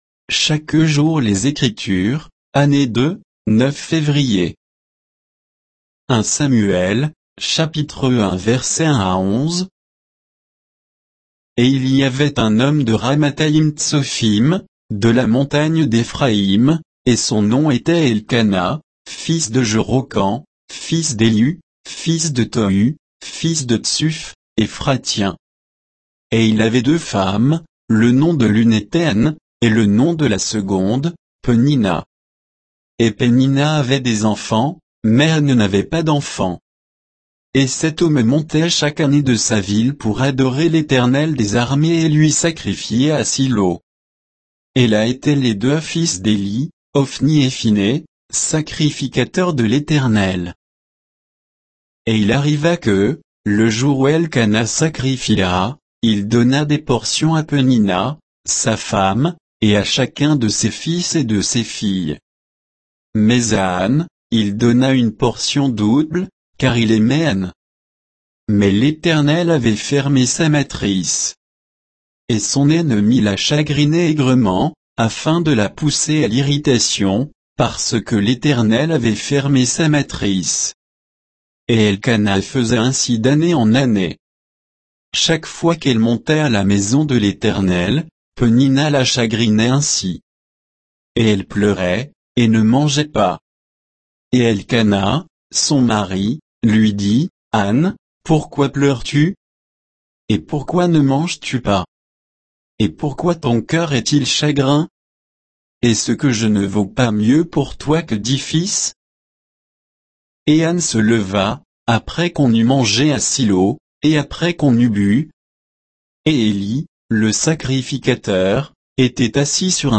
Méditation quoditienne de Chaque jour les Écritures sur 1 Samuel 1, 1 à 11